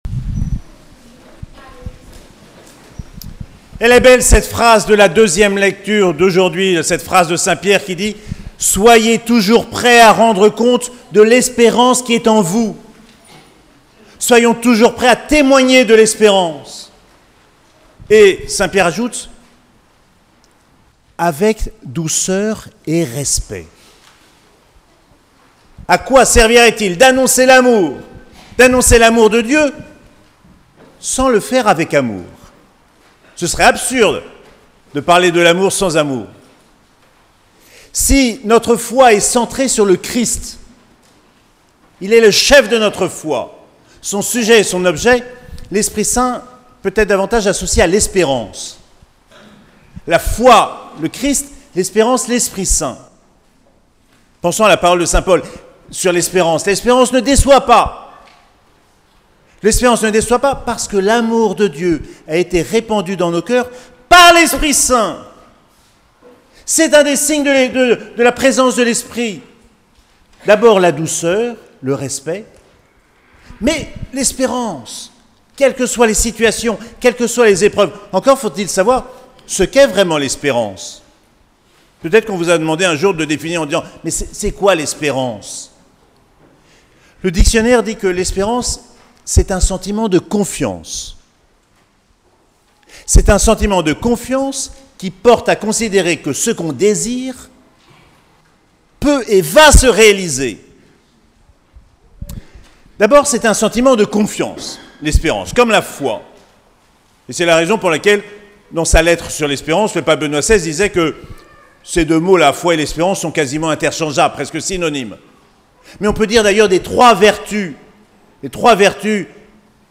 6ème dimanche de Pâques - 14 mai 2023
Toutes les homélies